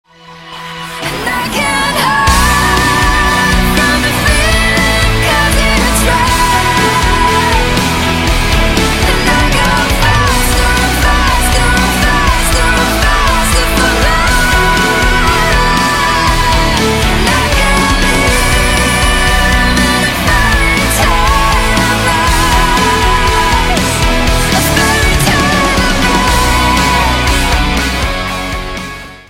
красивые
женский вокал
Progressive rock